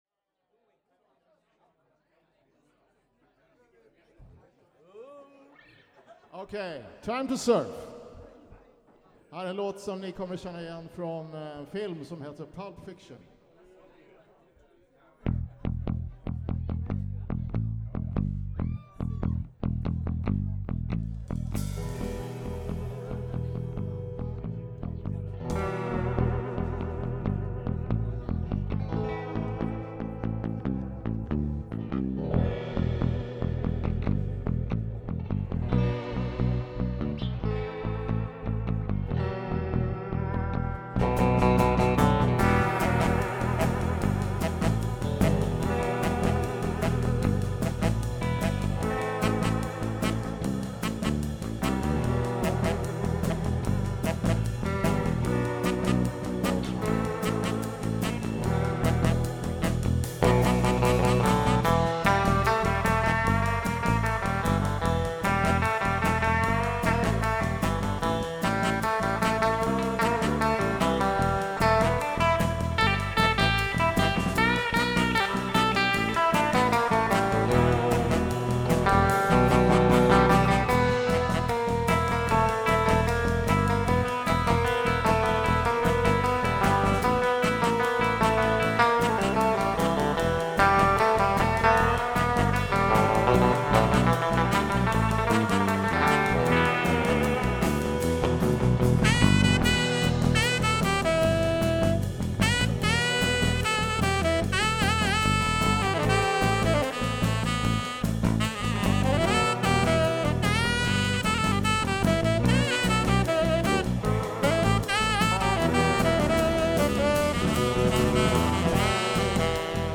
Trummor & Sång
Gitarr & Sång
Saxofon